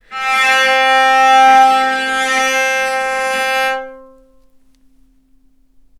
healing-soundscapes/Sound Banks/HSS_OP_Pack/Strings/cello/sul-ponticello/vc_sp-C4-ff.AIF at a9e67f78423e021ad120367b292ef116f2e4de49
vc_sp-C4-ff.AIF